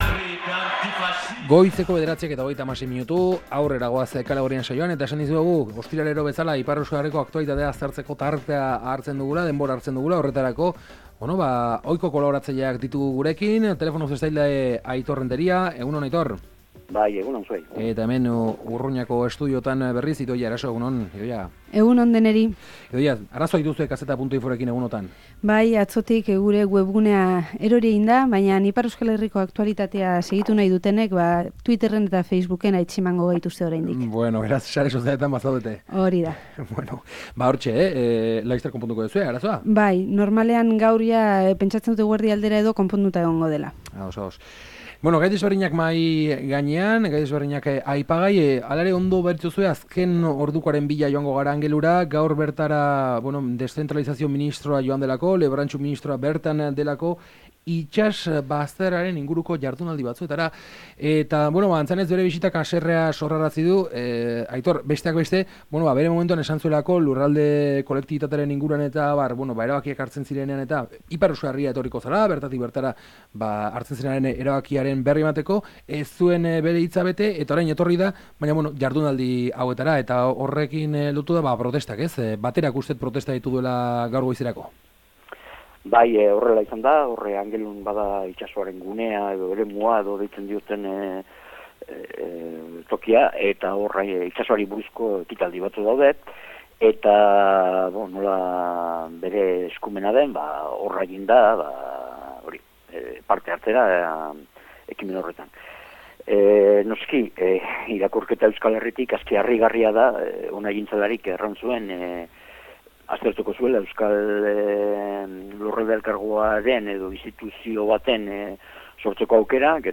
Ostiralero bezala, iparraldeko aktualitatea aztertu eta komentatzen dugu mahai inguruan.